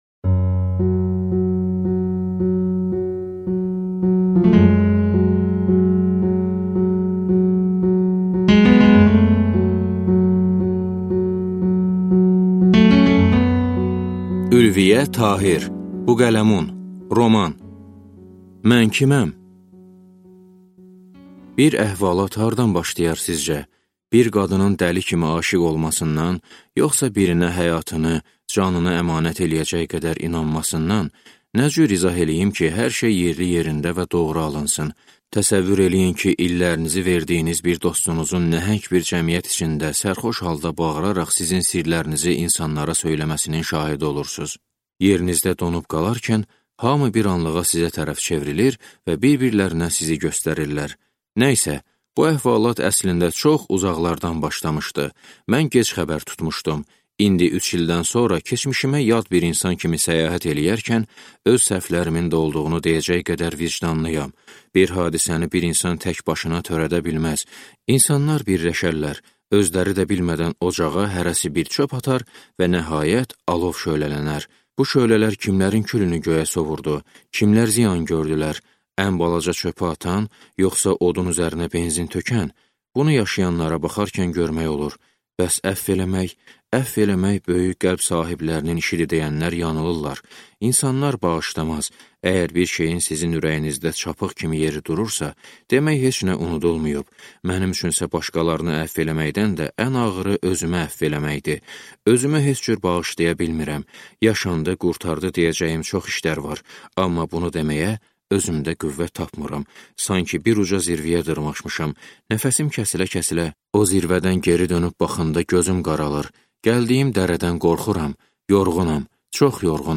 Aудиокнига Buqələmun